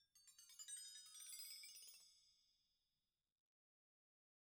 Percussion
BellTree_Stroke1_v1_Sum.wav